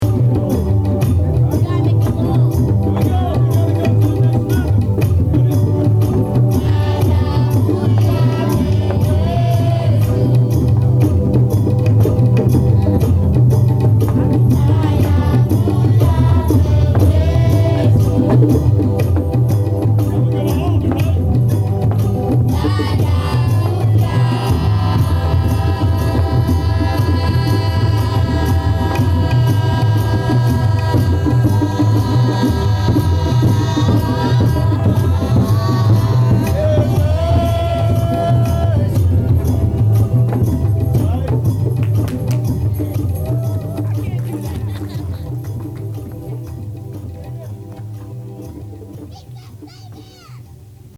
Live recordings from Stetson!
Swahili Chant of Deliverence |
chant.mp3